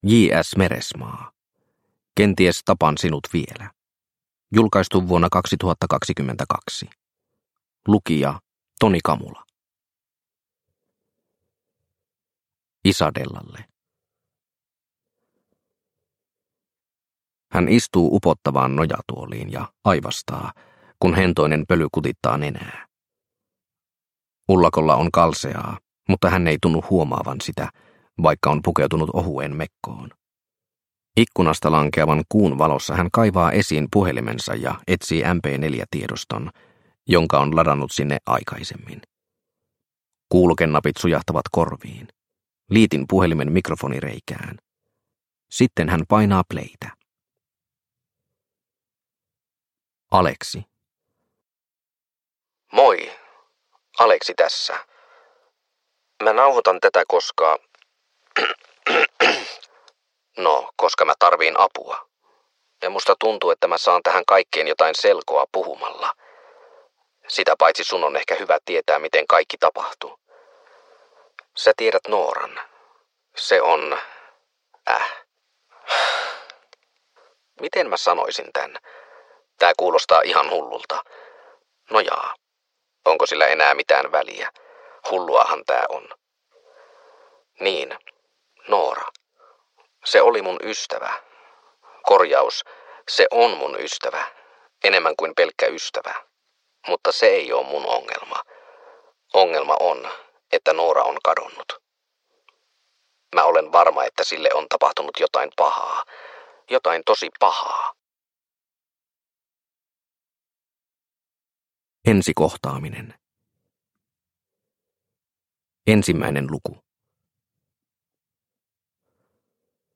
Kenties tapan sinut vielä – Ljudbok – Laddas ner